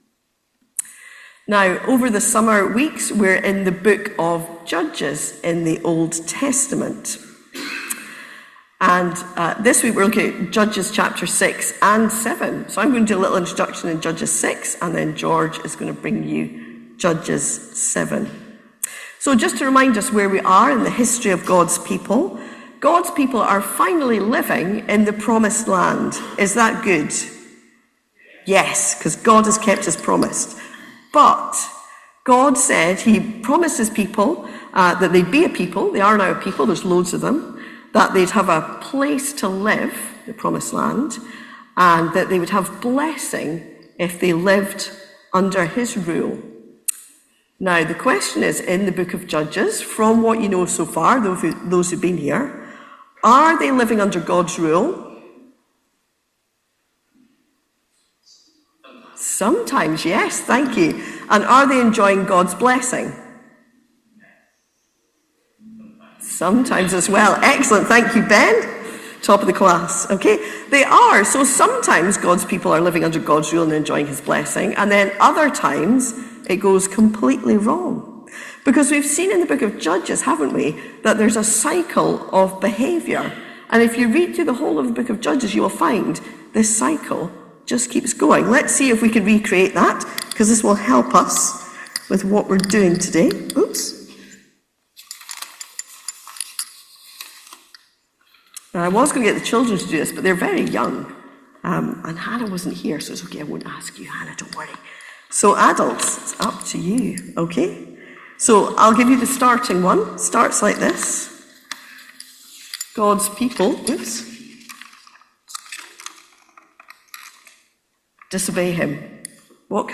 In Sunday Morning